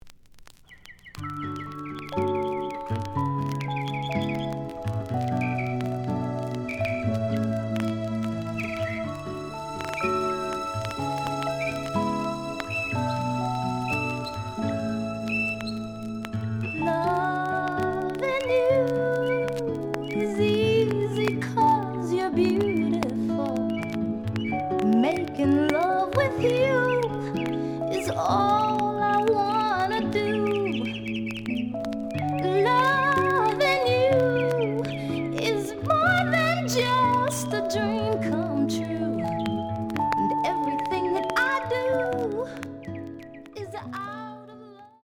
The audio sample is recorded from the actual item.
●Genre: Soul, 70's Soul
Some click noise on both sides due to scratches.)